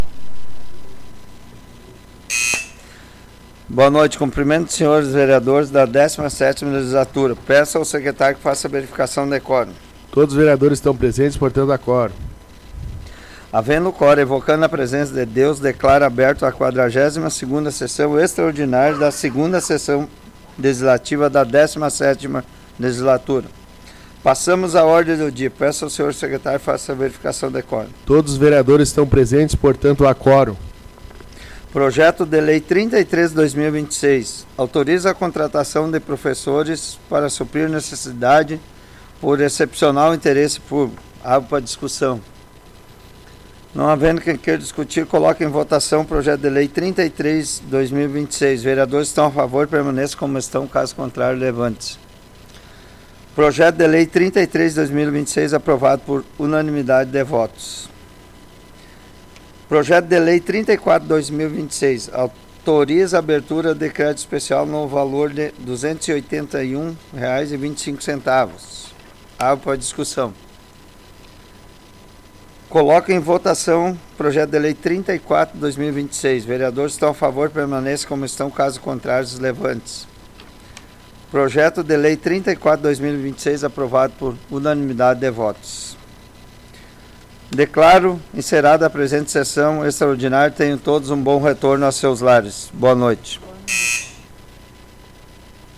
Áudio da 42ª Sessão Plenária Extraordinária da 17ª Legislatura, de 13 de abril de 2026
20_SessãoPlenáriaExtraordinária_13_04_2026.mp3